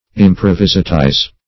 Improvisatize \Im`pro*vis"a*tize\, v. t. & i. Same as Improvisate .